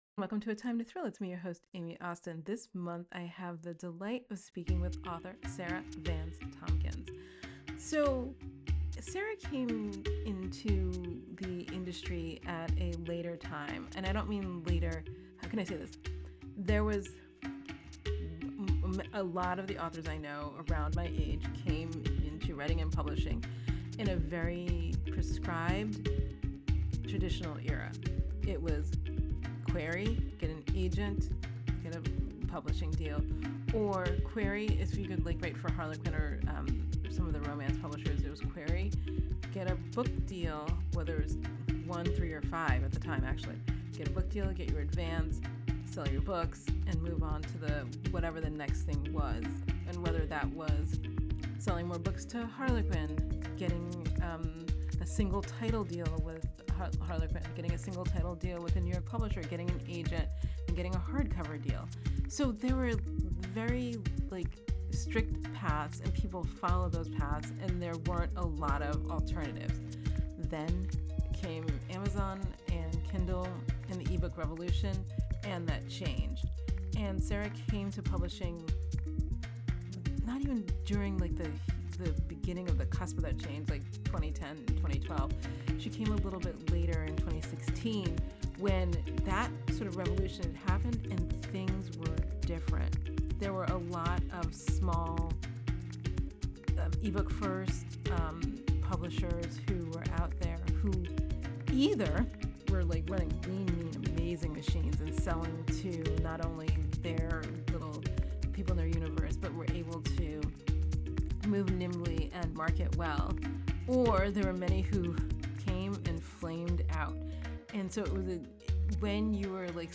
A Time to Thrill – Conversation